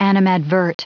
Prononciation du mot animadvert en anglais (fichier audio)